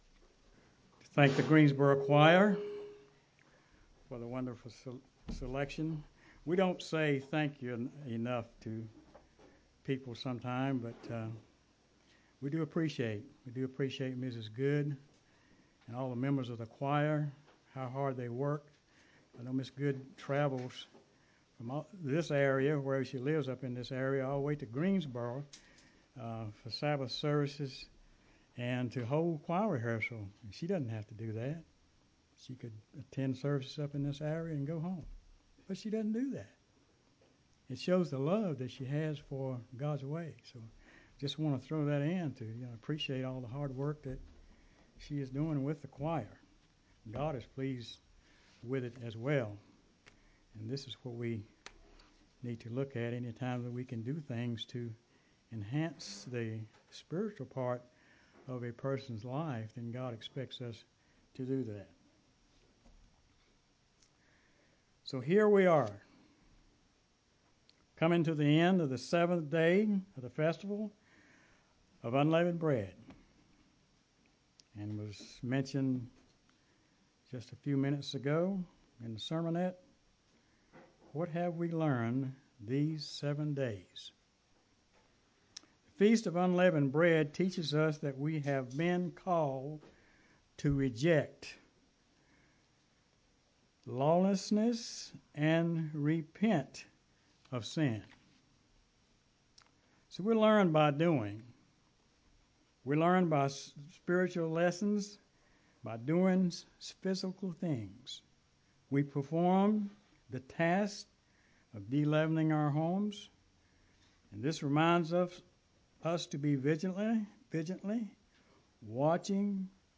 UCG Sermon Studying the bible?
Given in Greensboro, NC